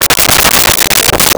Chickens In A Barn 05
Chickens in a Barn 05.wav